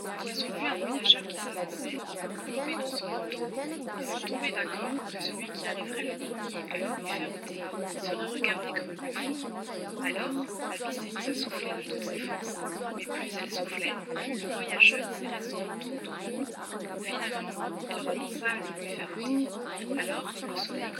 Le signal est ici la voix française de l’ISTS (NFIM french) et le bruit un mélange de 4 ISTS, le tout de -12dB à +12dB de RSB + silence.
En couplage ouvert au même RSB dans la cabine :
RSB -7dB open